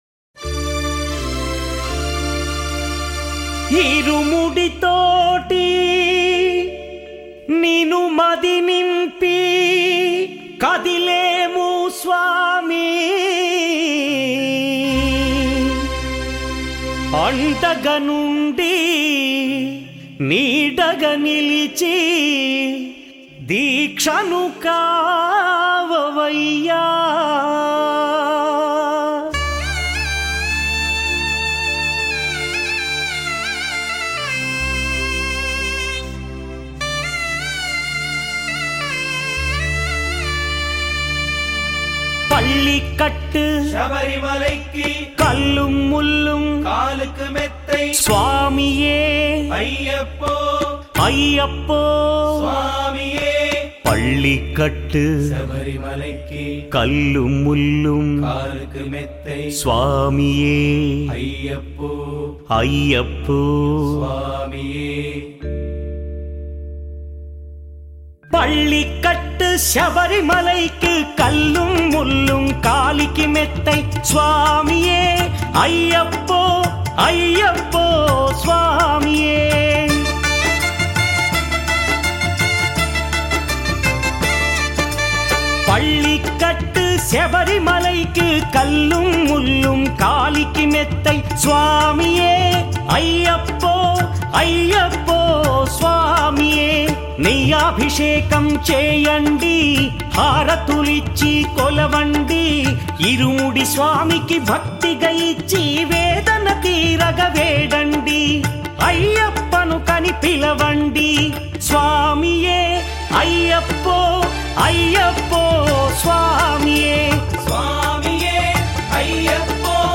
Telugu Devotional Songs